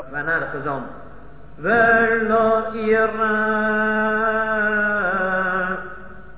Chazzan always repeats